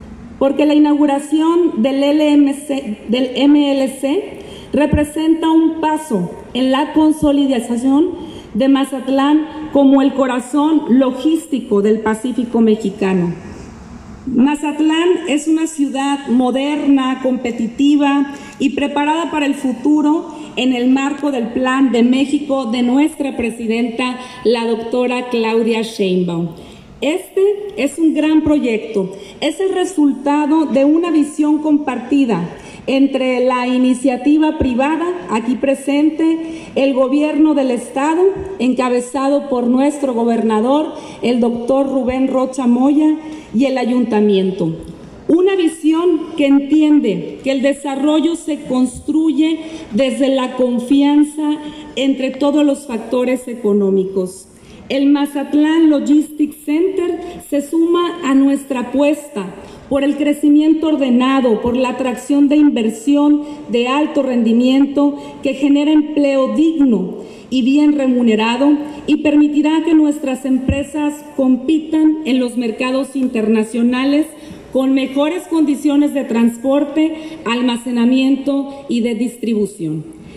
Estrella-Palacios-alcaldesa-de-Mazatlan-_-Inauguracion-de-MLC.mp3